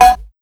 150UKCOWB1-L.wav